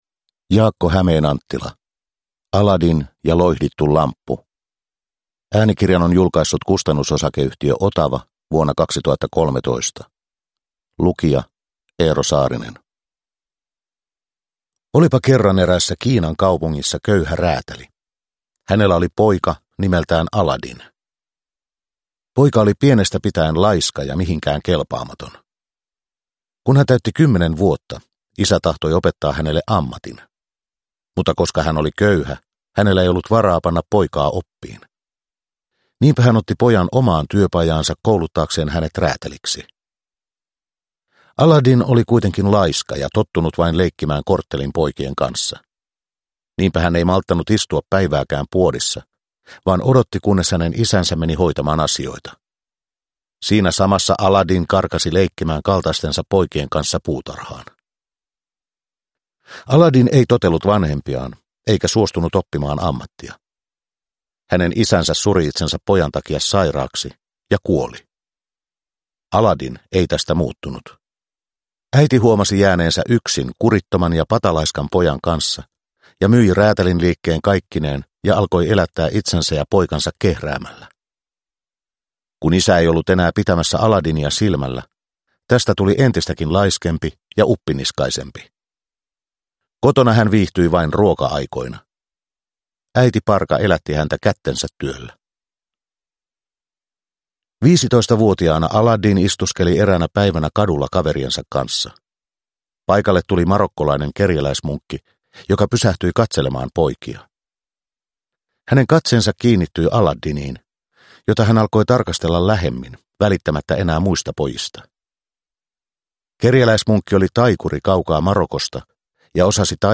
Aladdin ja loihdittu lamppu – Ljudbok – Laddas ner